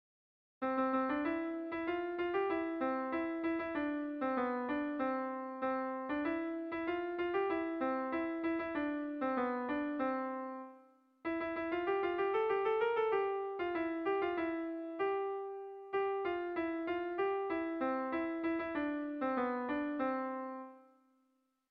Kontakizunezkoa
Zortziko handia (hg) / Lau puntuko handia (ip)
AABA